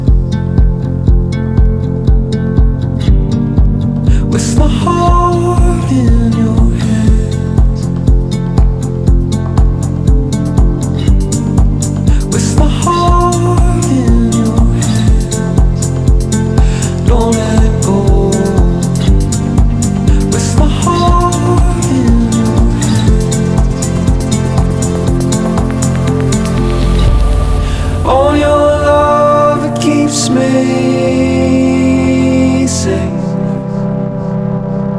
Жанр: Электроника
# Electronic